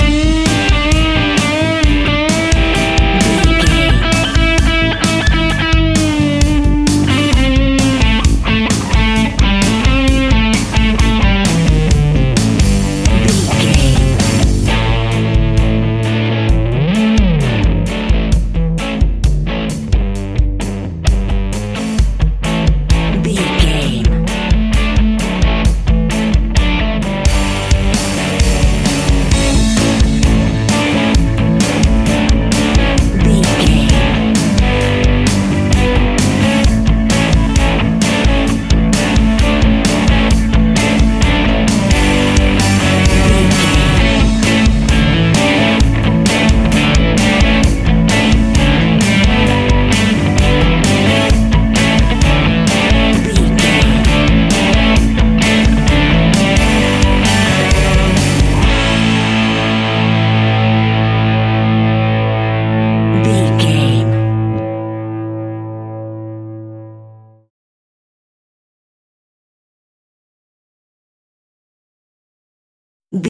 Ionian/Major
energetic
driving
heavy
aggressive
electric guitar
bass guitar
drums
hard rock
heavy metal
blues rock
distortion
instrumentals
heavy drums
distorted guitars
hammond organ